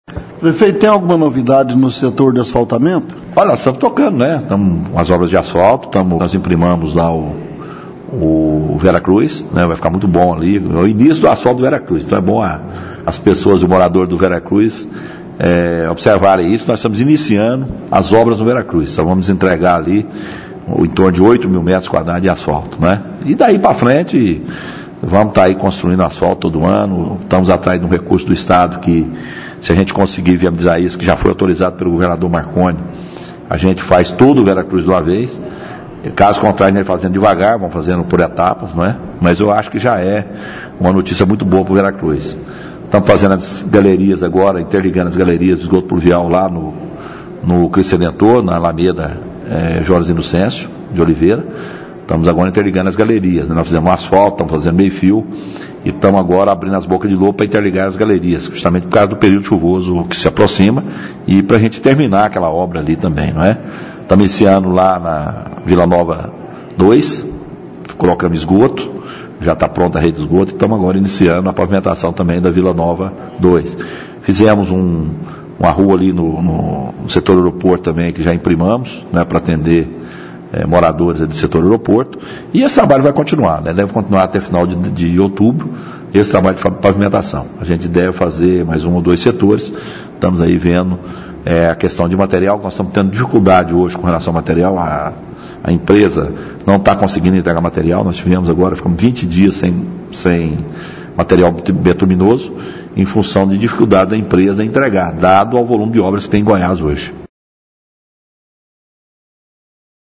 A Prefeitura de Morrinhos continua as obras de asfaltamento do Setor Vera Cruz. As duas primeiras ruas já estão imprimadas, penúltima etapa dos trabalhos de pavimentação. O prefeito Rogério Troncoso fala sobre esta e várias outras obras espalhadas pela cidade